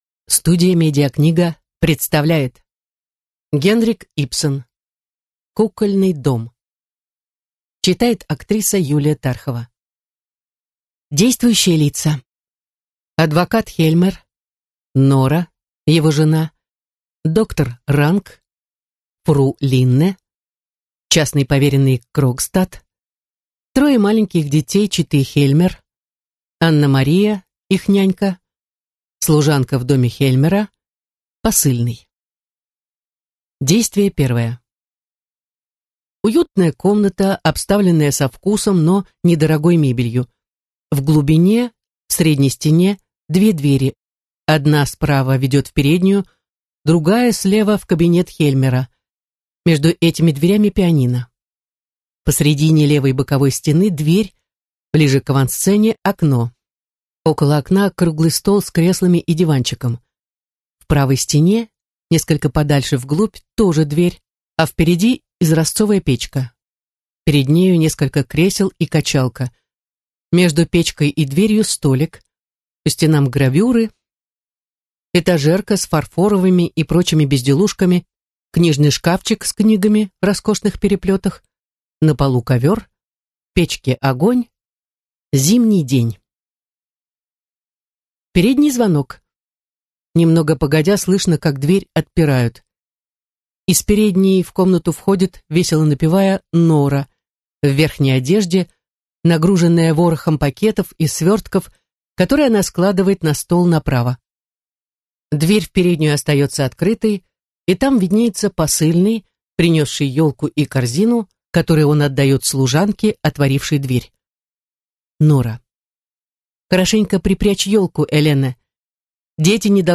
Аудиокнига Кукольный дом | Библиотека аудиокниг